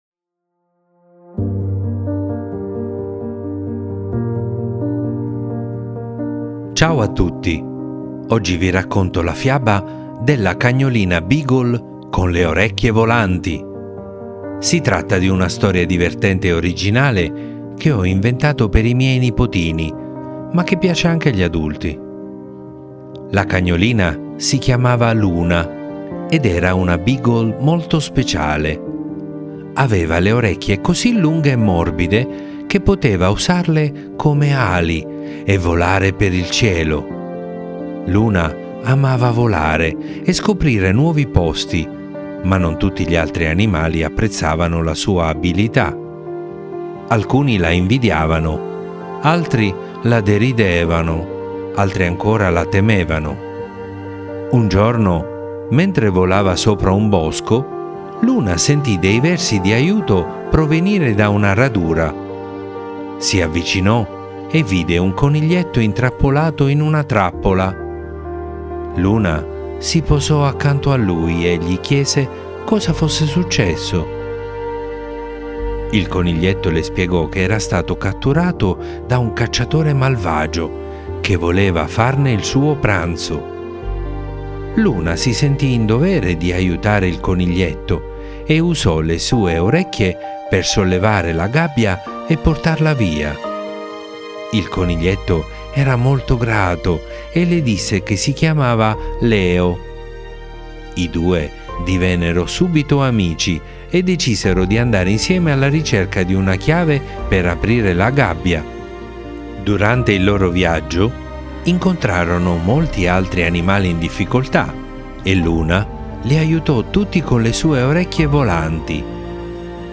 mamma legge la fiaba
Le favole della buonanotte